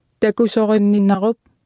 Speech synthesis Martha to computer or mobile phone
Speech Synthesis Martha